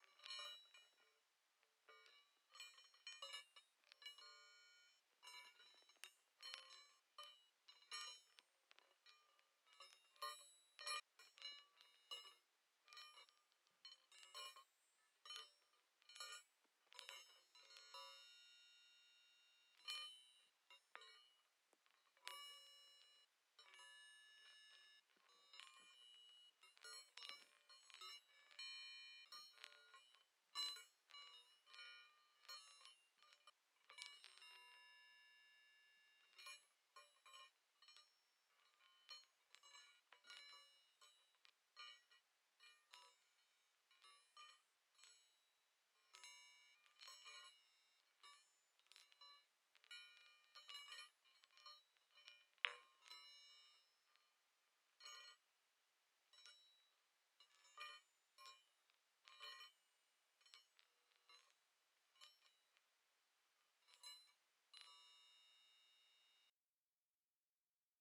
Baoding Balls